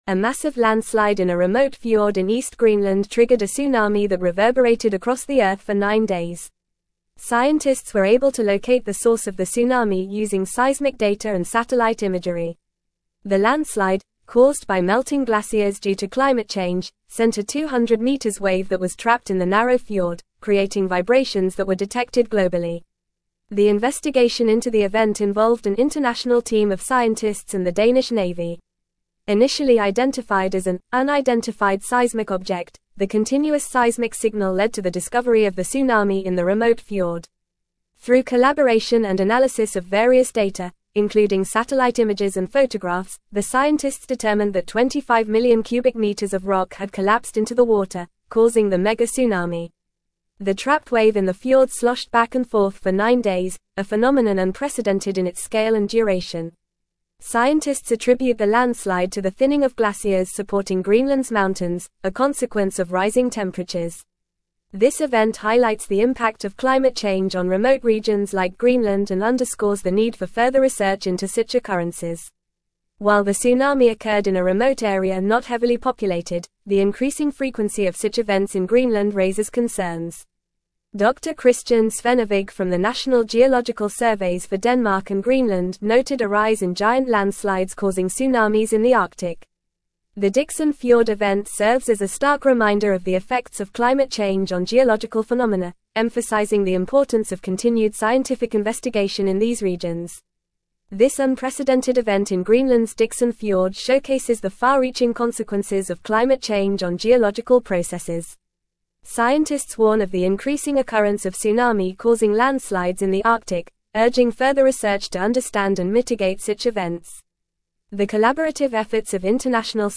Fast
English-Newsroom-Advanced-FAST-Reading-Greenland-Tsunami-Scientists-Unravel-Cause-of-Mysterious-Tremors.mp3